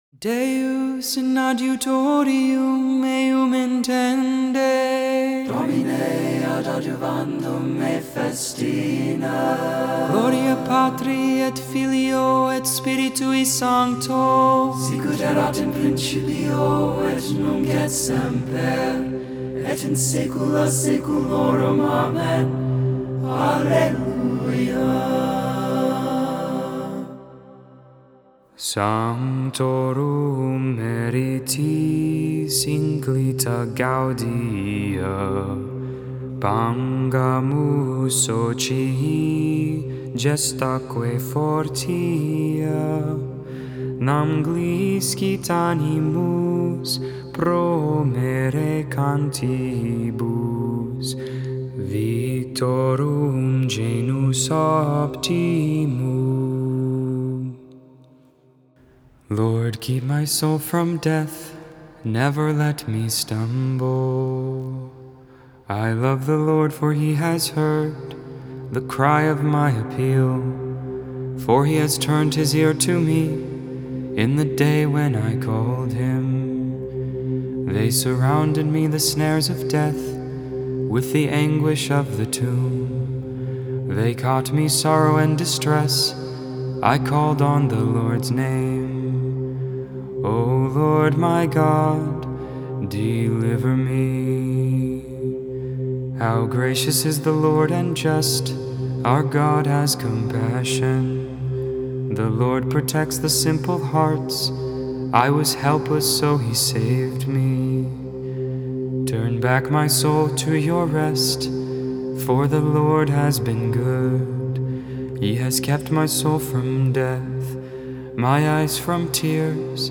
1.22.21 Vespers (Friday Evening Prayer)
Vespers for the 2nd Friday in Ordinary Time.